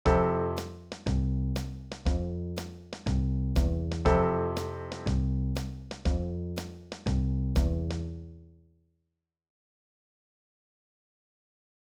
2ビートを弾くときのポイント
2ビート　曲.wav